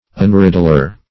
Unriddler \Un*rid"dler\, n.